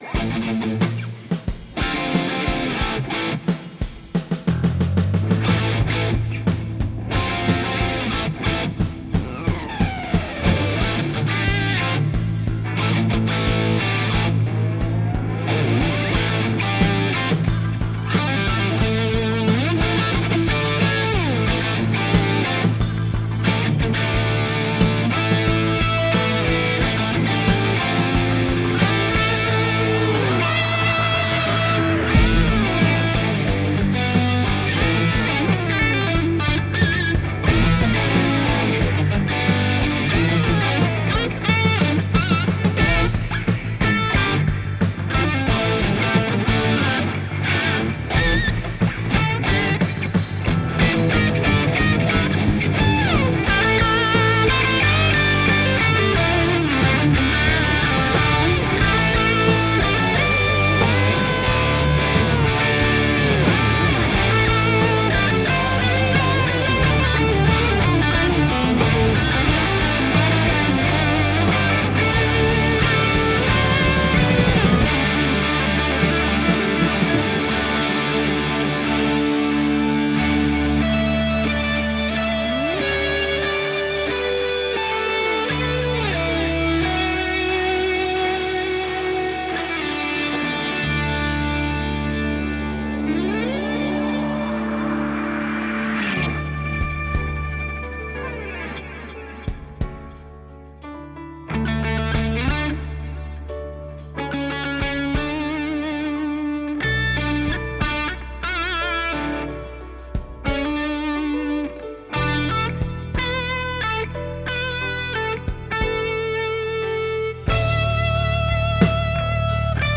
Meaning I would get two completely different sounding end results IF my idea of inspiration and “feel” for the media is in fact true.
I think it’s clear which is digital, but I think the character of both is totally different and I wonder which one has your preference.
Tried to get the levels as close as possible.